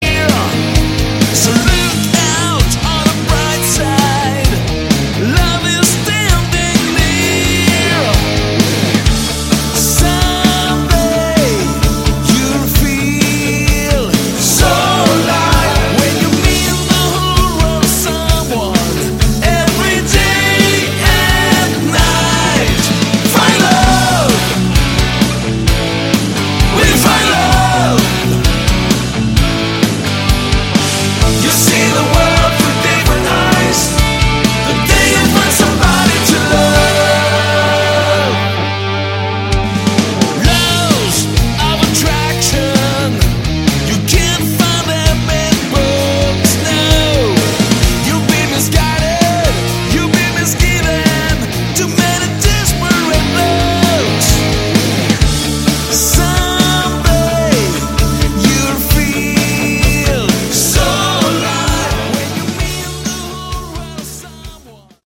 Category: AOR / Melodic Rock
Lead Vocals, Keyboards, Backing Vocals
Drums, Percussion